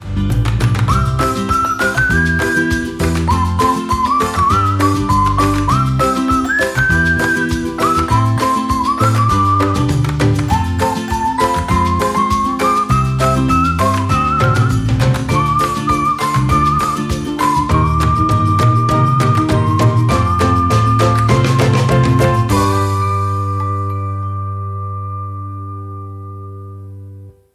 Нарезка на смс или будильник